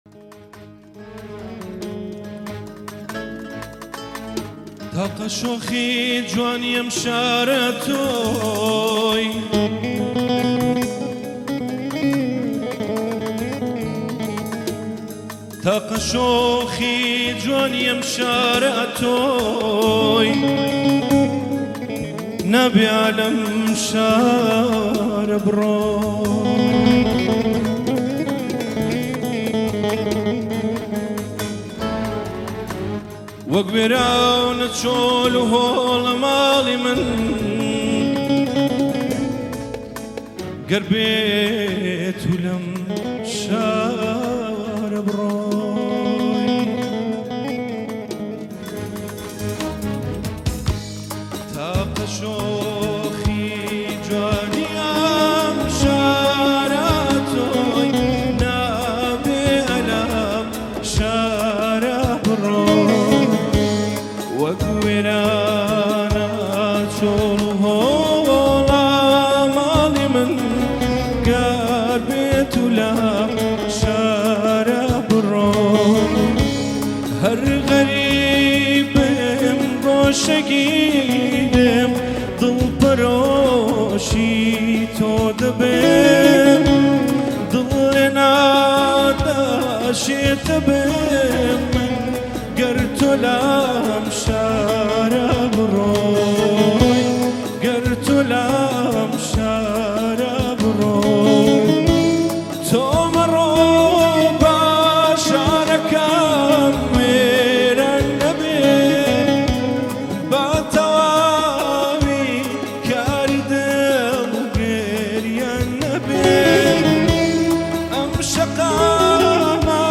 آهنگ کردی شاد